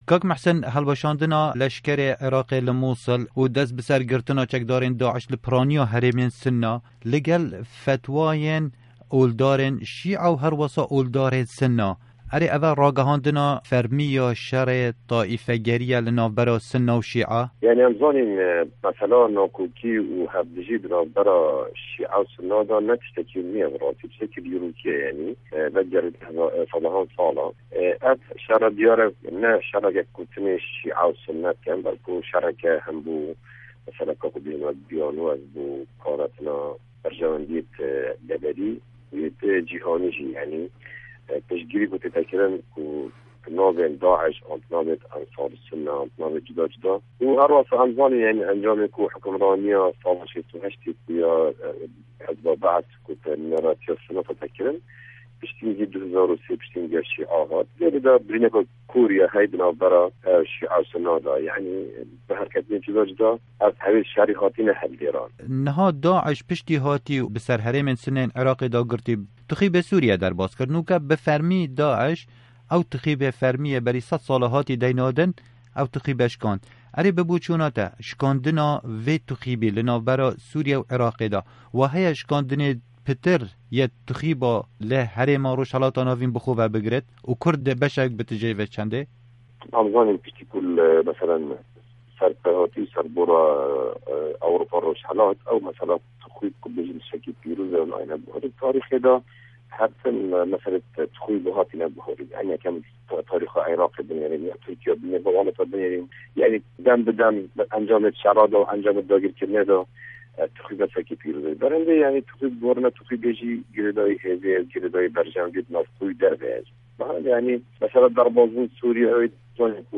Di hevpeyvînekê di ligel Dengê Amerîka endamê parlamana Iraqî Muhsin Osman dibêje, hêz û rewşa herêmê û herwisa ya cîhanê ye.
Hevpeyvîn bi Muhsîn Osman re